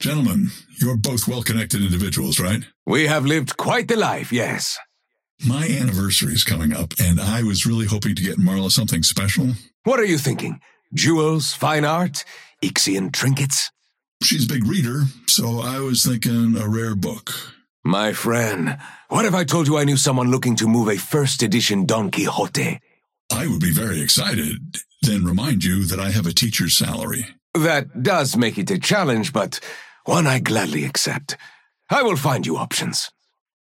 Dynamo and Krill conversation 3